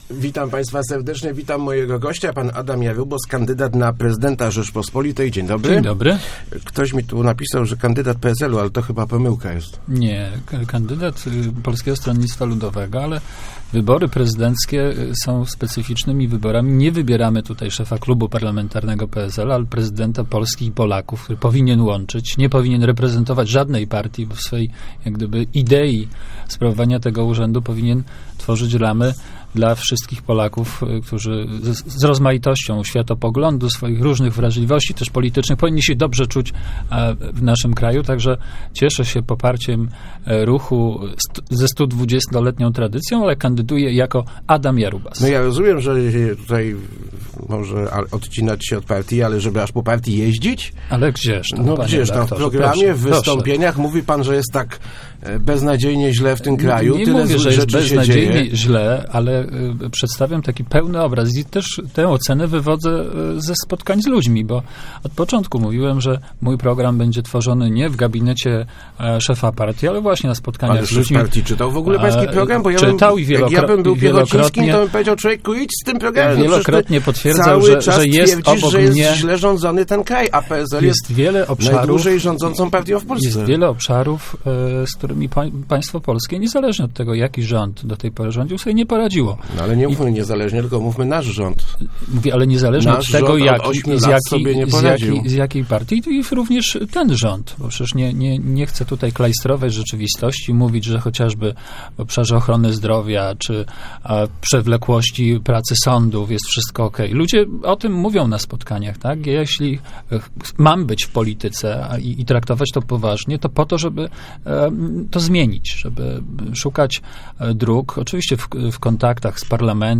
jarubs.jpgNie chcę klajstrować rzeczywistości i mówić, że wszystko w Polsce jest OK - mówił w Rozmowach Elki Adam Jarubas, kandydat PSL na Prezydenta Zapewnił, że prezydent może zrobić dużo - sam proponuje szereg ustaw mających poprawić sytuację demograficzną.